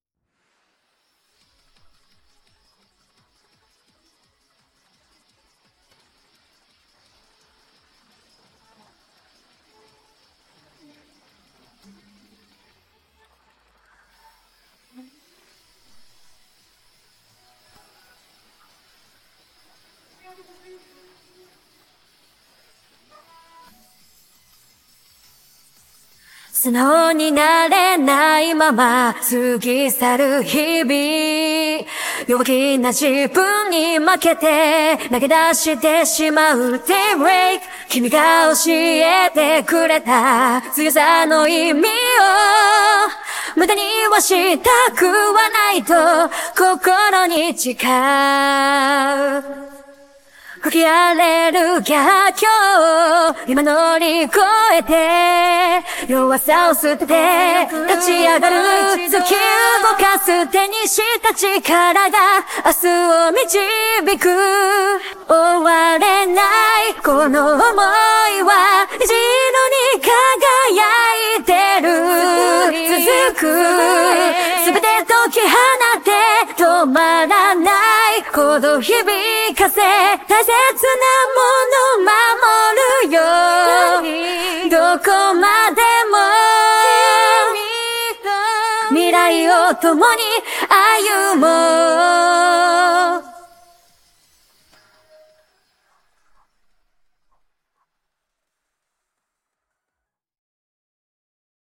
以下のように原曲、ボーカル、インストュルメンタルの3つのデータに分けられました。
Vocal
正直な感想ですが、かなりの分離精度にビックリしました。